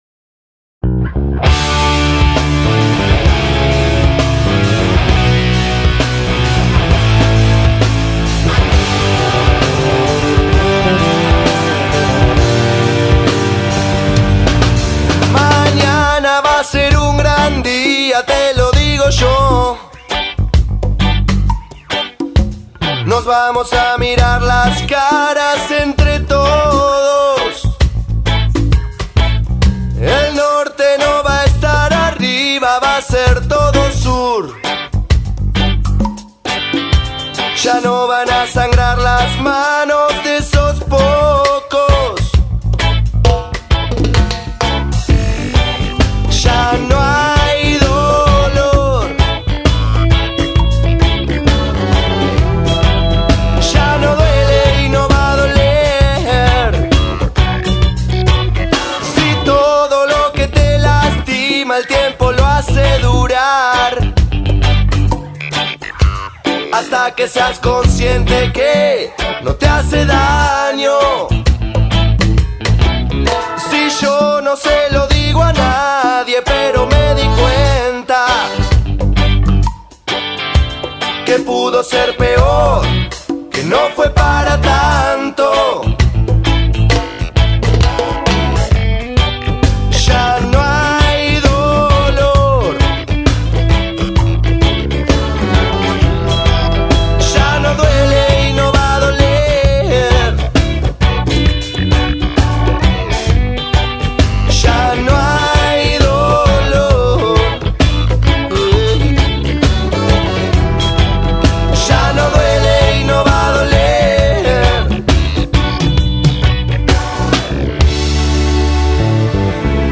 Carpeta: Rock uruguayo mp3